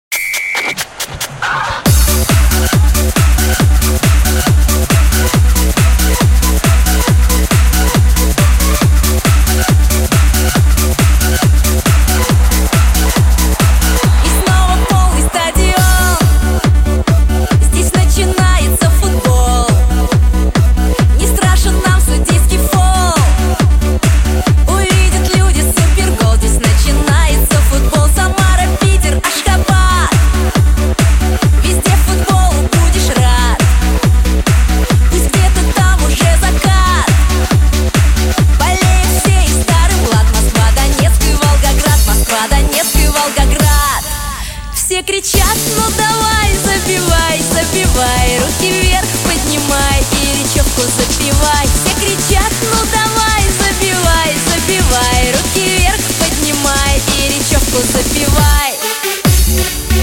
Pop
лучшие образцы танцевальной музыки